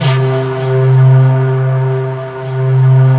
SYNPIPE C2-R.wav